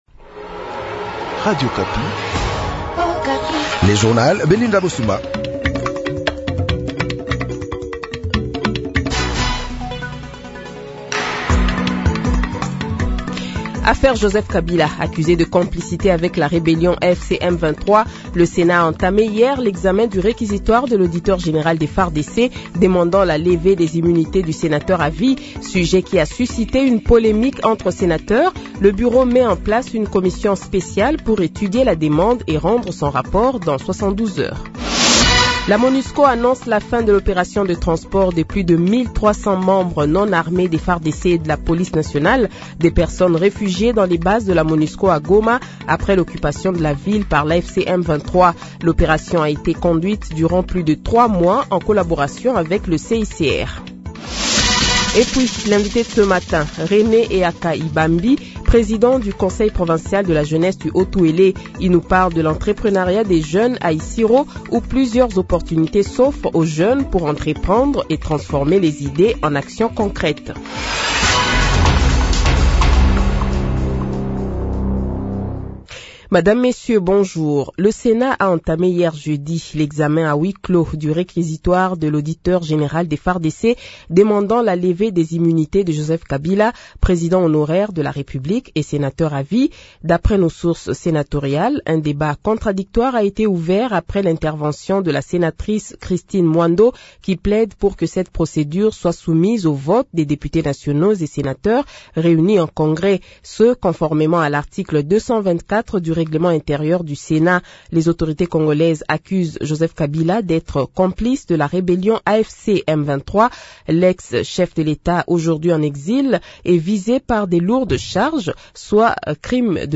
Le Journal de 7h, 16 Mai 2025 :